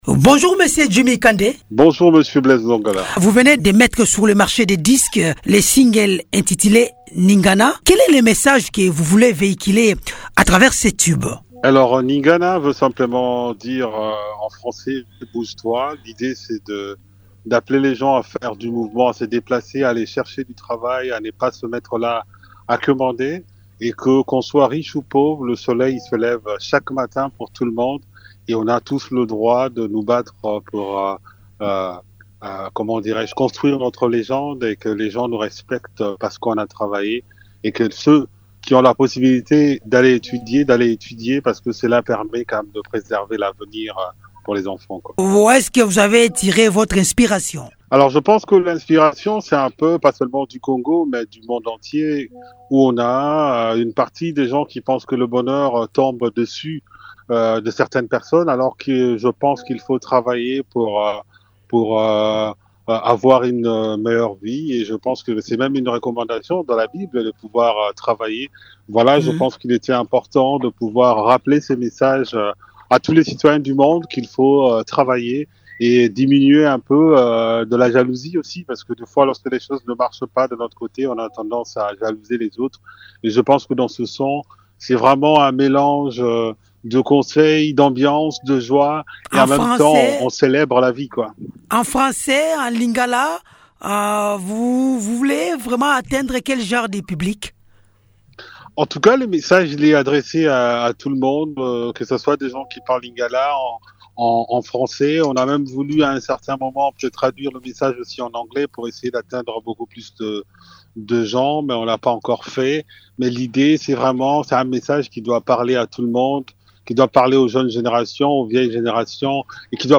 répond aux questions de